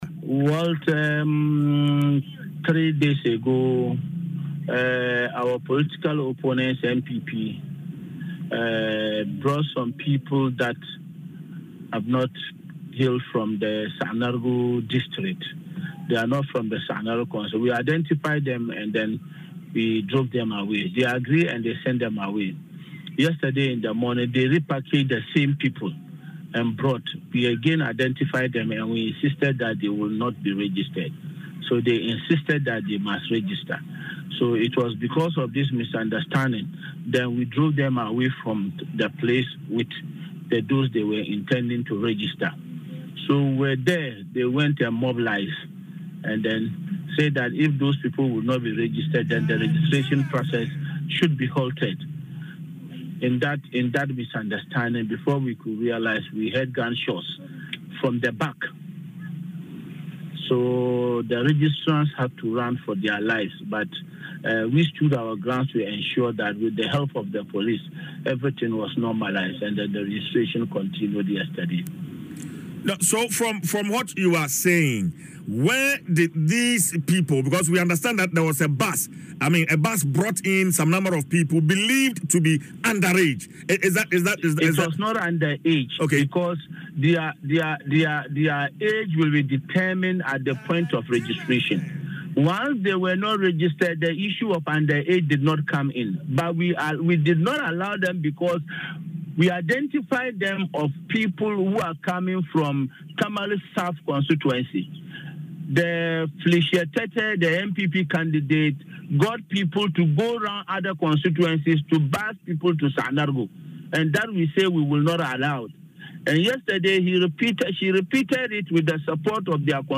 interview
morning show Dwaso Nsem